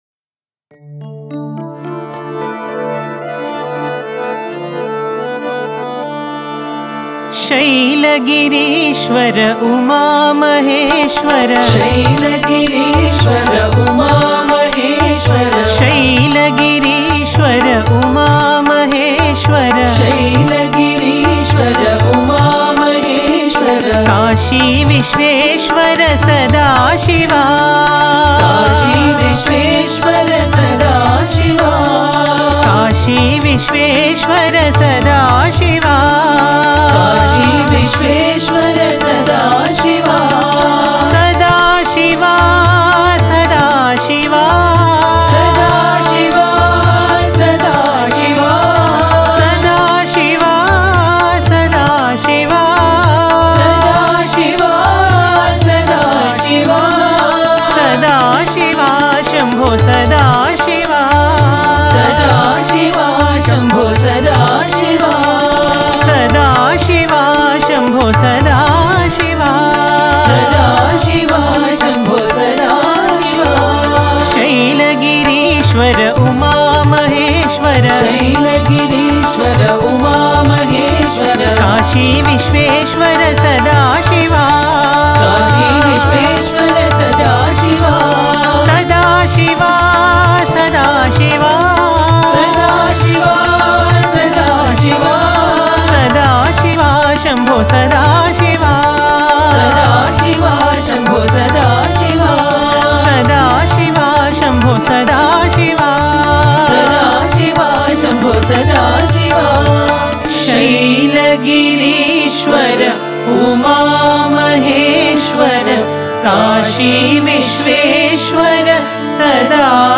Home | Bhajan | Bhajans on various Deities | Shiva Bhajans | 47 Shaila Gireeshwara Uma Maheshwara